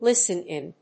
アクセントlísten ín